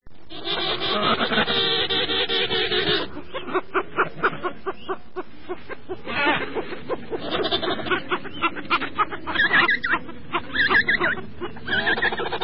penguins
penguin.mp3